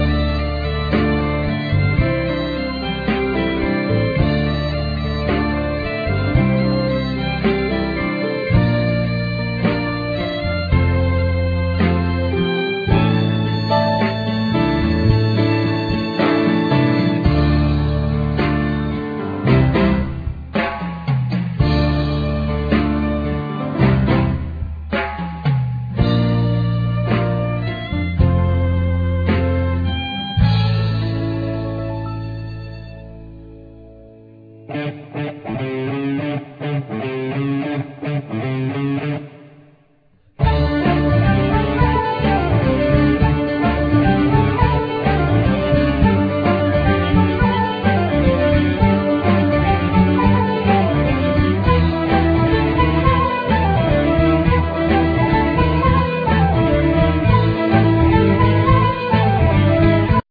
Vocal
Flute,Travesera,Gaita
Violin,Mandolin
Ud,Buzuki,Zanfona,Percussions